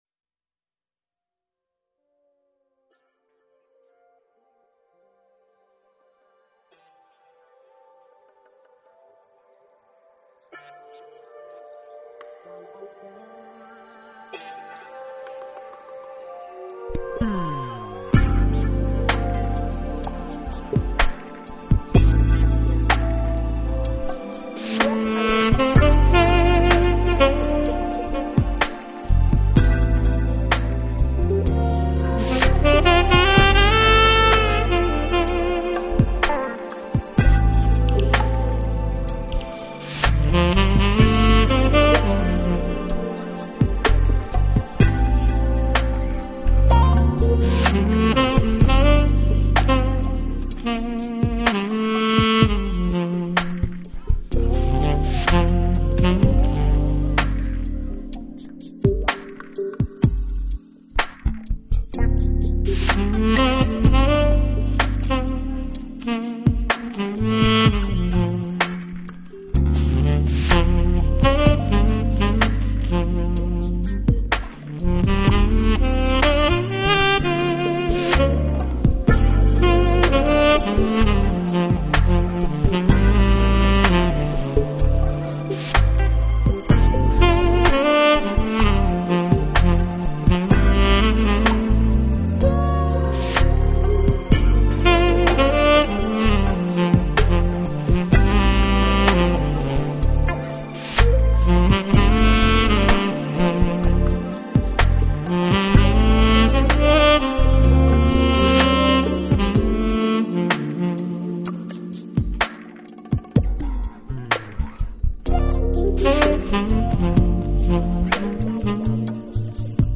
音乐类型：Jazz
而此曲的整体风格幽雅，